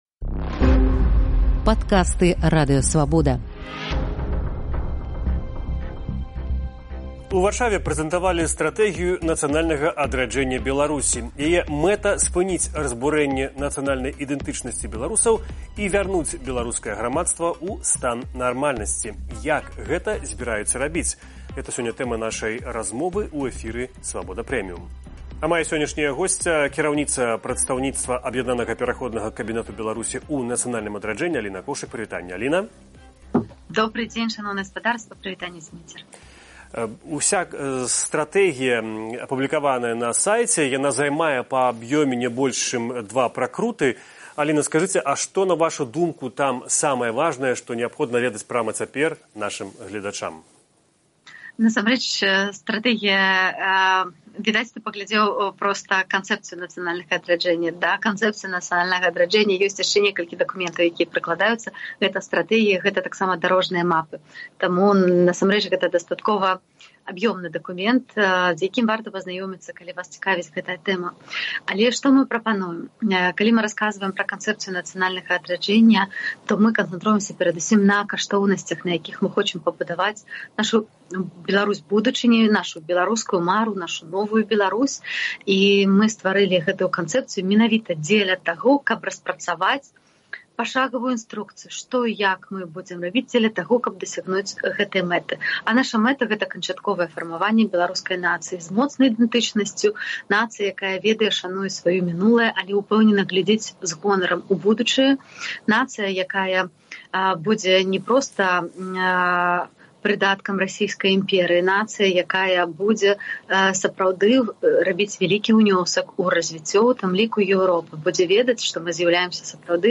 Пра гэта ў эфіры «Свабоды Premium» расказвае прадстаўніца Аб’яднанага пераходнага кабінэту ў нацыянальным адраджэньні Аліна Коўшык.